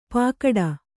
♪ pākaḍa